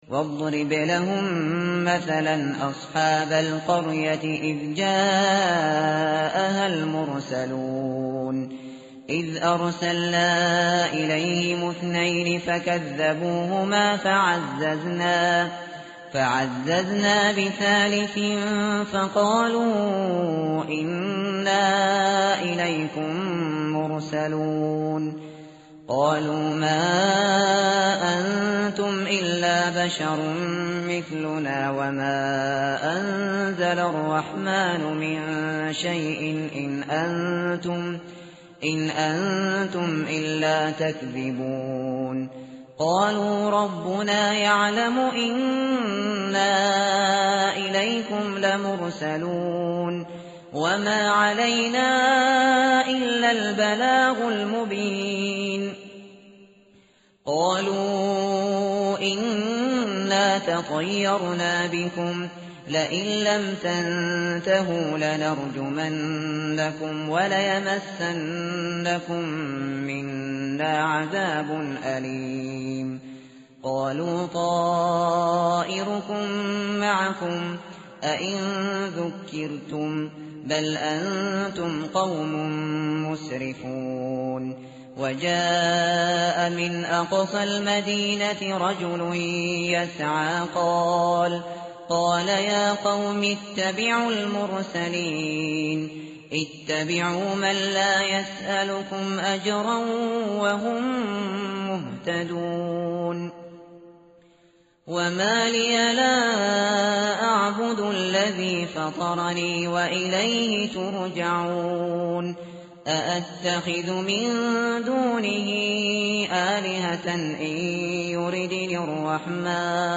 tartil_shateri_page_441.mp3